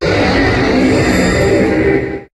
Cri d'Engloutyran dans Pokémon HOME.